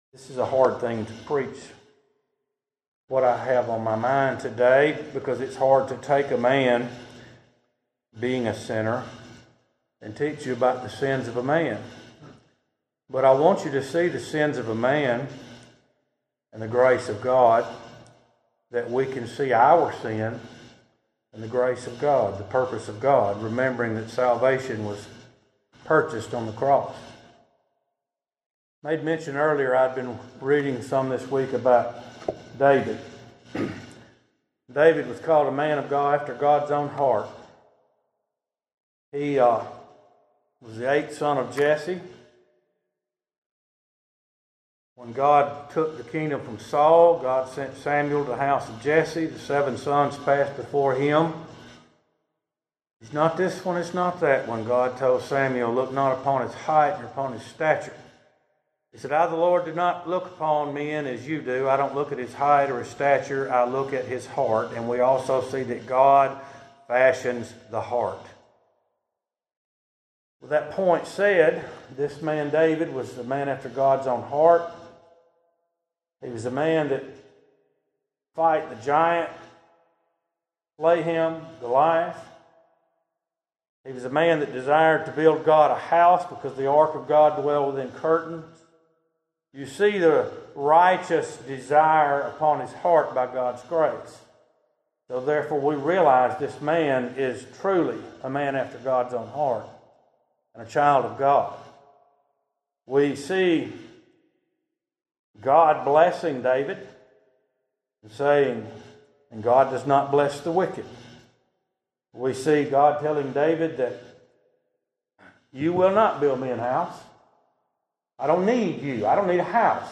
message from 2 Samuel chapter 11.